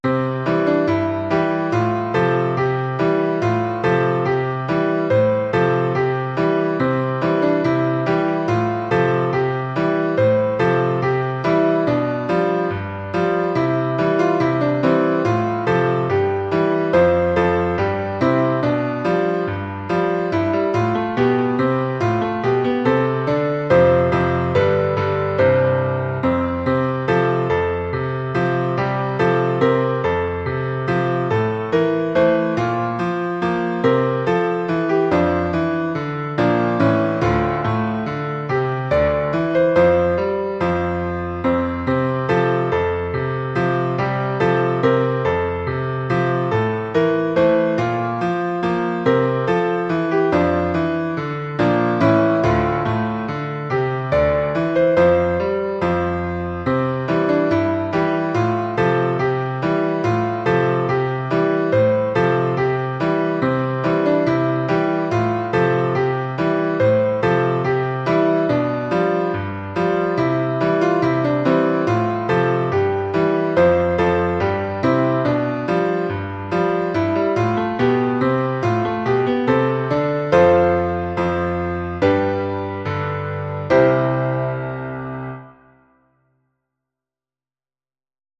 團歌
基督小先鋒 純音樂 (版本2).mp3